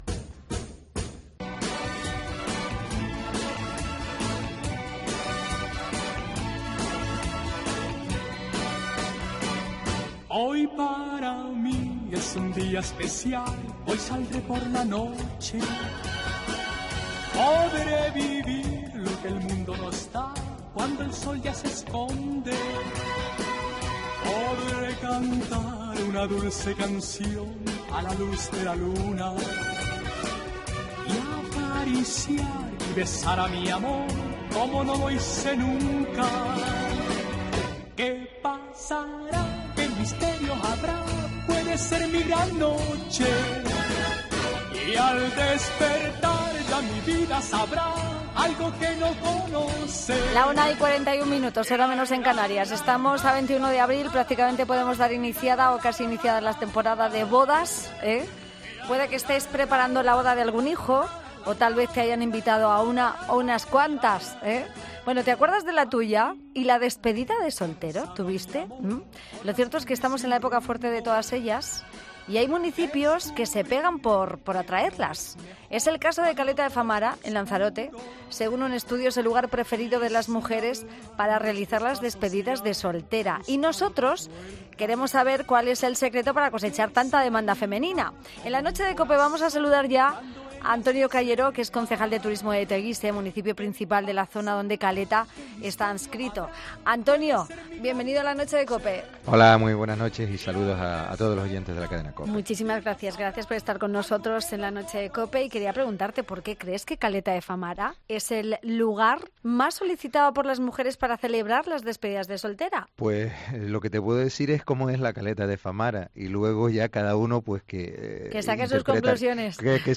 Y también lo debatimos en esta entrevista.